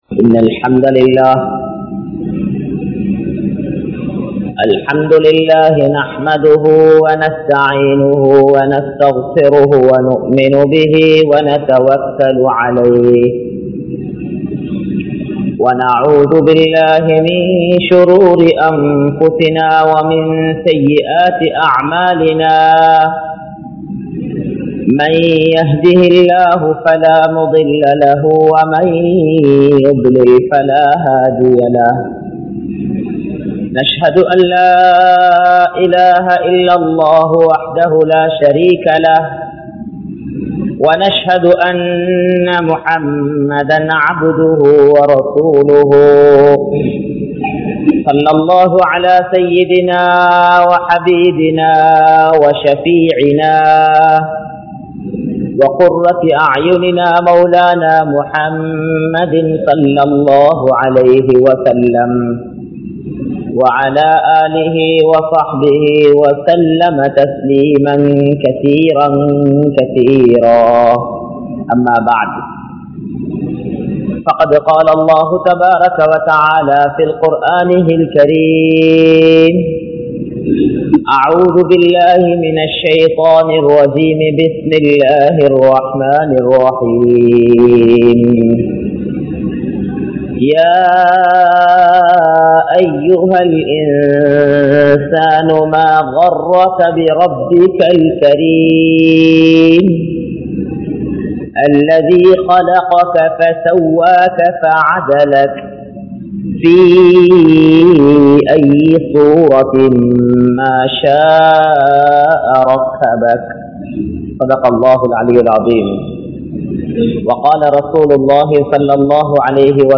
Al Quranum Hadheesum Indru Avasiyama? (அல்குர்ஆனும் ஹதீஸூம் இன்று அவசியமா??) | Audio Bayans | All Ceylon Muslim Youth Community | Addalaichenai